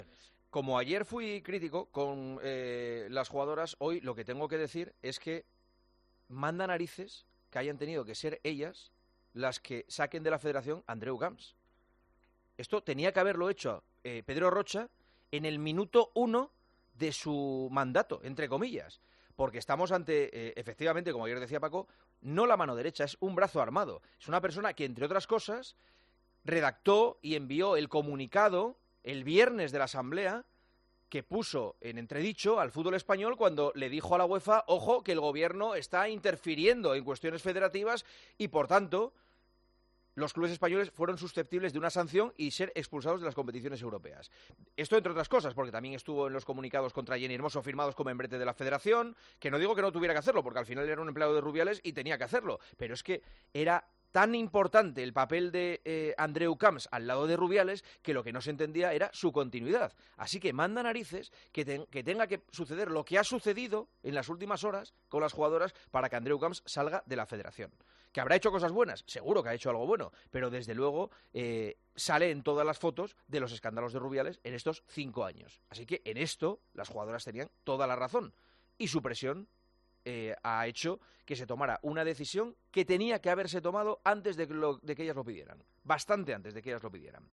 AUDIO: El presentador de El Partidazo de COPE destacó una decisión de Pedro Rocha, el nuevo presidente de la RFEF, que no entiende que haya llegado tan tarde...